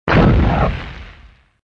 collision_projectile_ast.wav